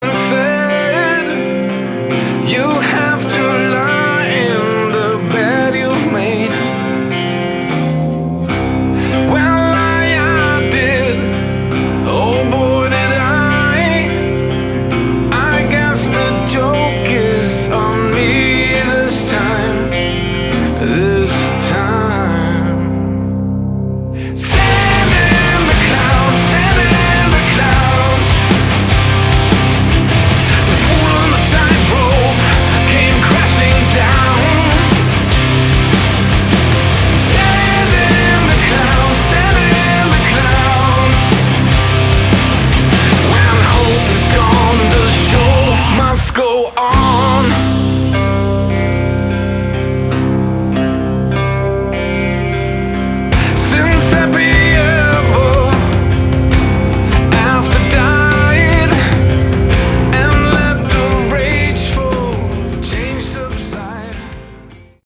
Category: Hard Rock
vocals
bass
drums
guitars